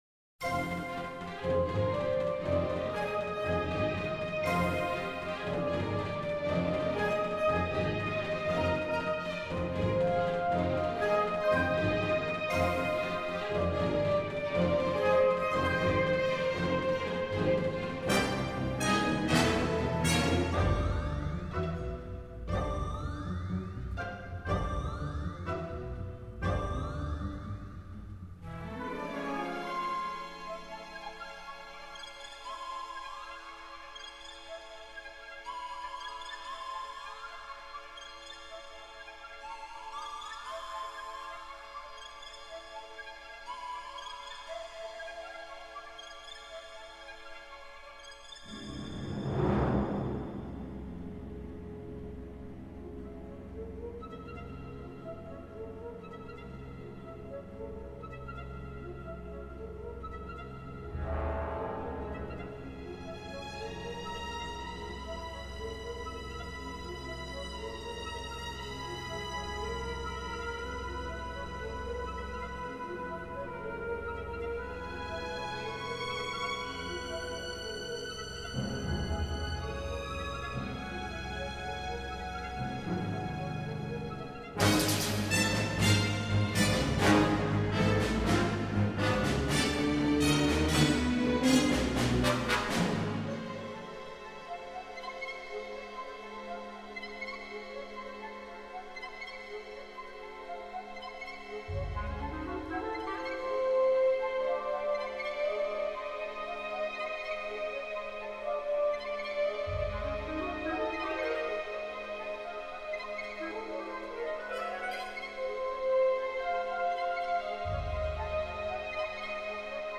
Genre: Classical, Stage & Screen
Style: Soundtrack, Score, Neo-Classical, Contemporary